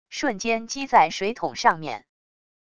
瞬间击在水桶上面wav音频